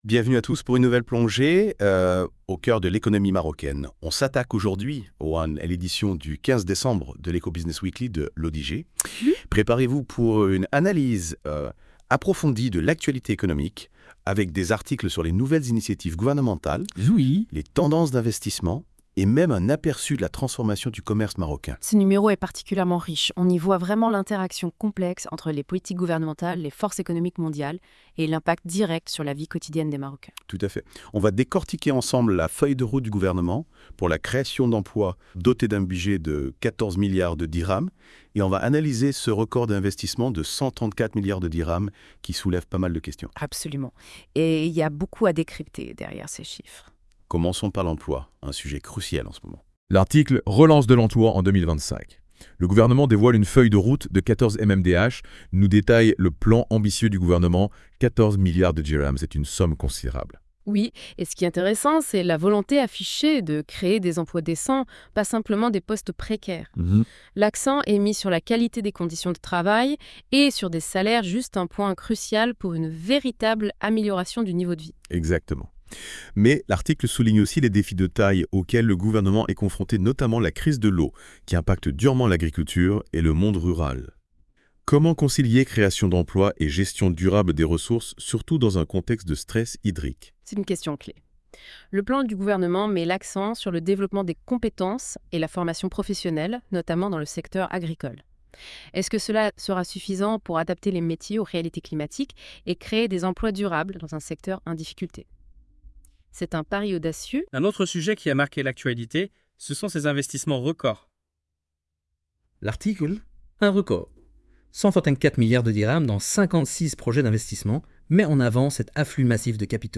+ Débat - Podcast : les chroniqueurs de la Web Radio débattent des idées contenues dans cet Hebdomadaire à travers ces questions